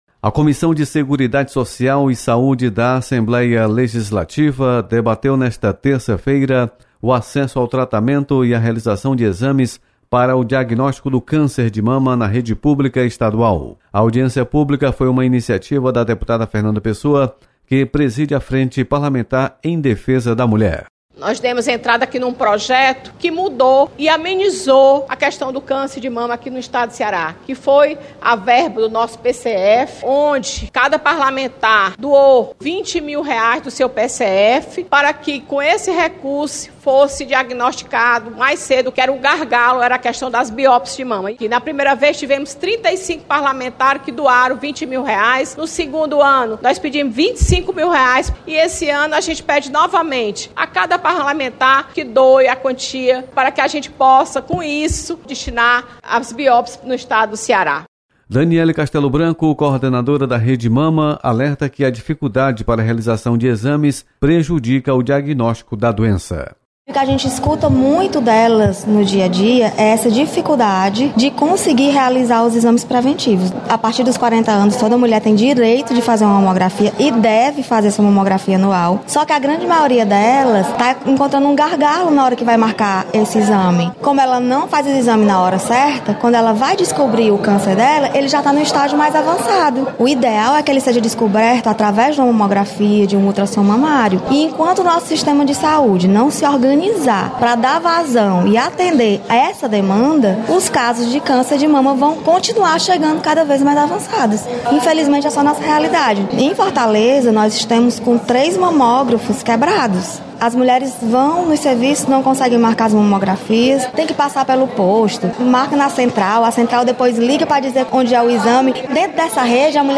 Audiência